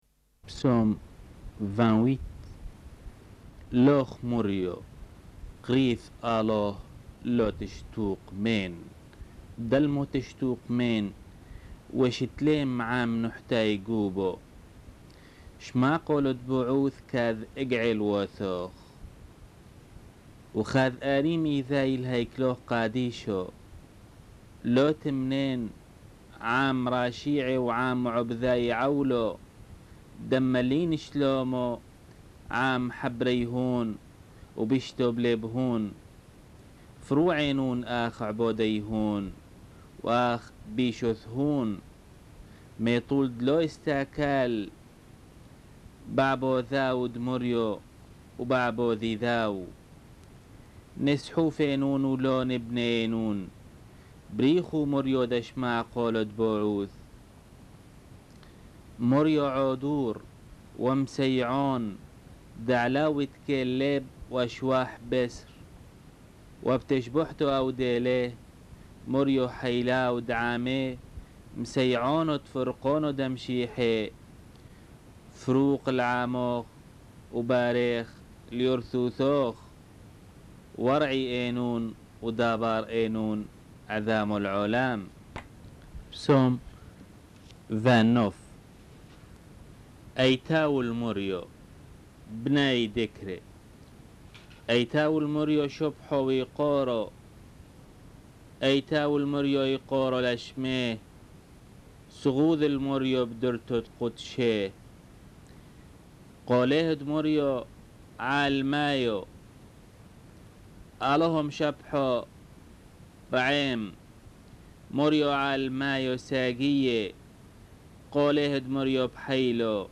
Enregistrement de la lecture des Psaumes (version syriaque)